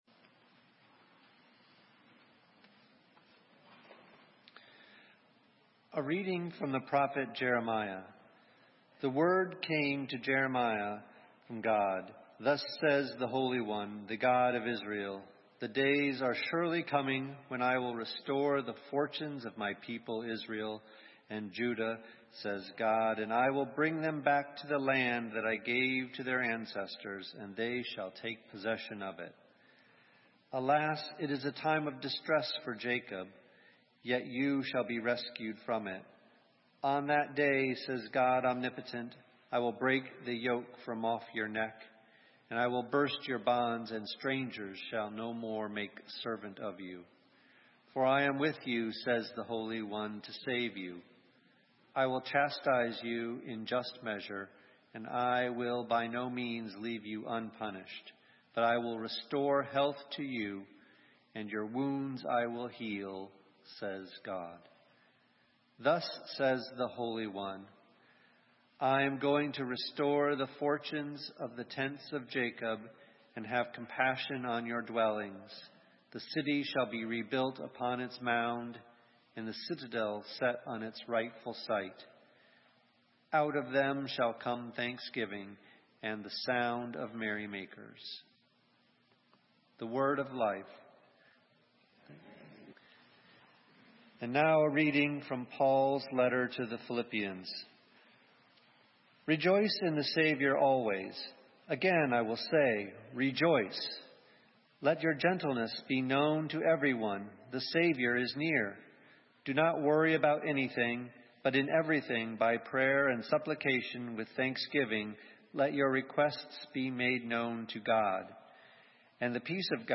Sermon:Gratitude - St. Matthews United Methodist Church